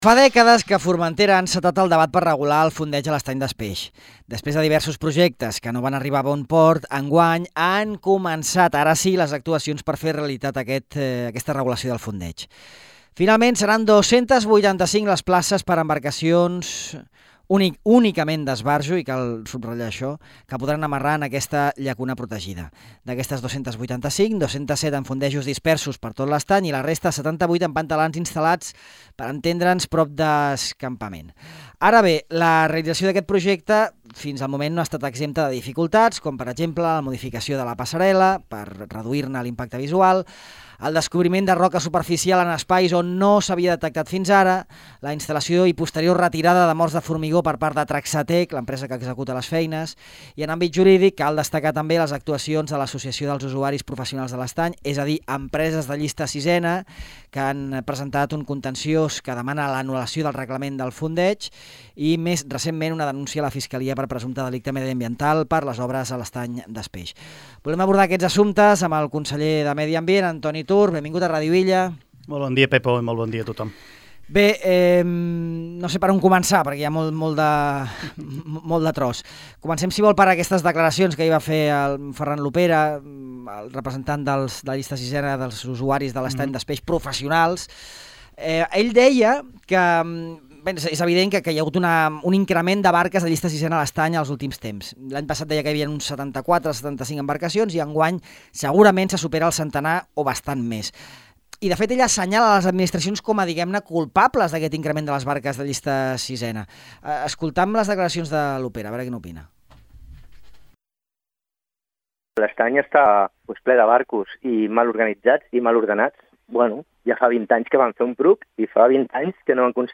El conseller insular de Medi Ambient, Antoni Tur, ha explicat en entrevista a Ràdio Illa (escoltau àudio) l’estat actual del projecte de regulació del fondeig a l’estany des Peix. Tur ha exposat que la previsió és que les feines, actualment paralitzades, es reprenguin prompte i que estiguin finalitzades aquest estiu, concretament “abans” de l’11 de setembre, que és la data en què expira la pròrroga que ha sol·licitat l’administració insular per executar l’obra i poder-se beneficiar dels 652.000 euros provinents de l’Impost de Turisme Sostenible (ITS).